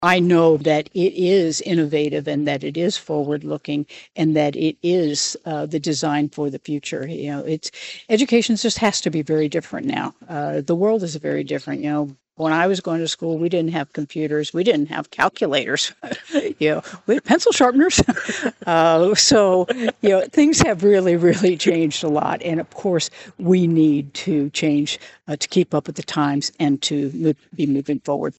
Kansas Governor Laura Kelly was on hand for Wednesday’s activities and says with the ever-evolving landscape of education, she believes the Jones Center is a perfect representation of how Kansas continues to evolve with it.